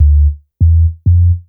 808s
Bass_77.wav